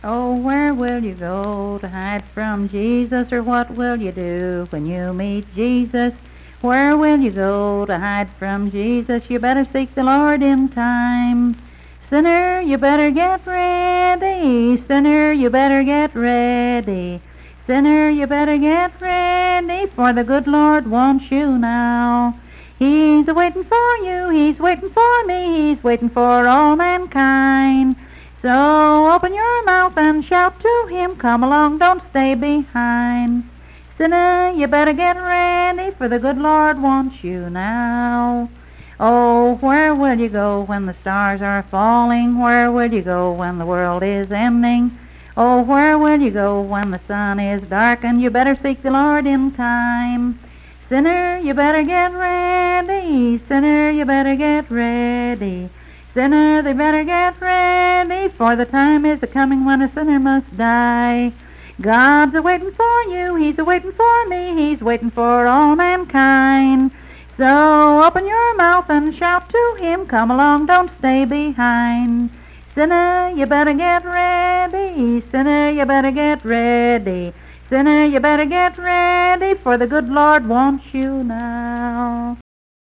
Unaccompanied vocal music performance
Hymns and Spiritual Music
Voice (sung)